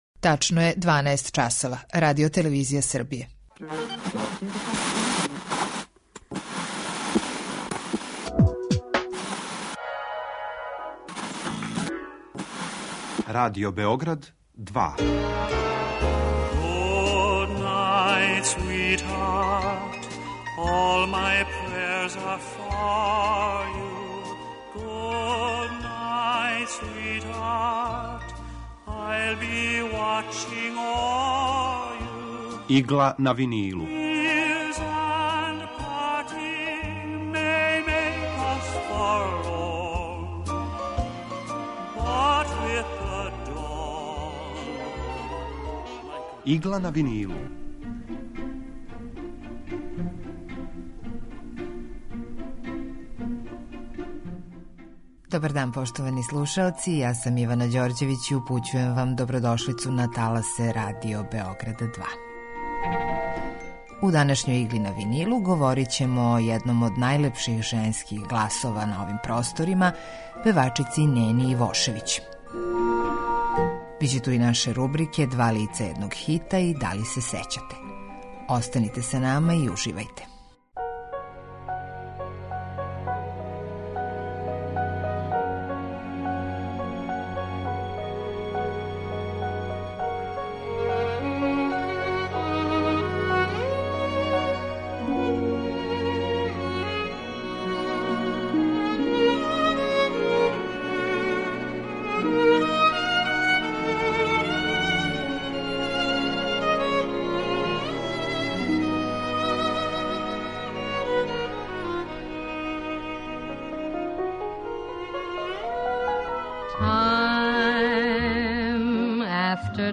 Евергрин музика
У Игли на винилу представљамо одабране композиције евергрин музике од краја 40-их до краја 70-их година 20. века. Свака емисија садржи кроки композитора / извођача и рубрику Два лица једног хита (две верзије исте композиције) [ детаљније ] Све епизоде серијала Аудио подкаст Радио Београд 2 Концерт Биг Бенда РТС-а у част стогодишњице рођења Боре Роковића.